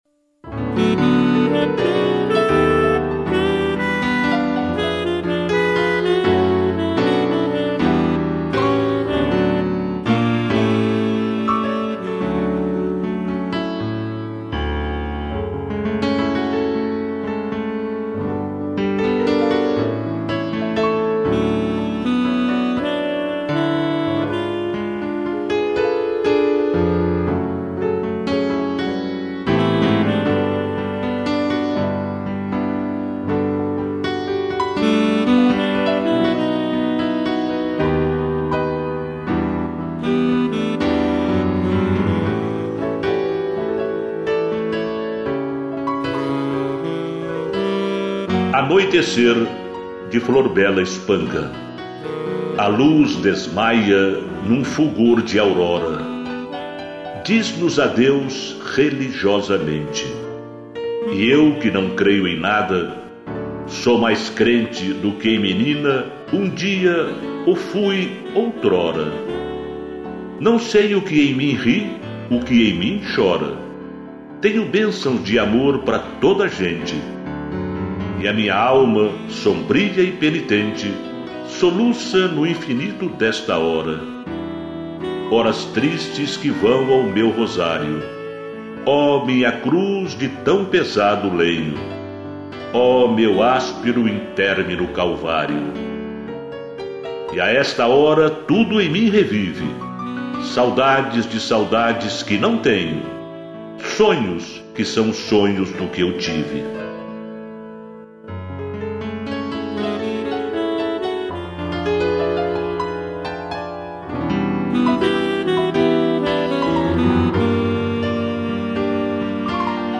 2 pianos e sax
interpretação do texto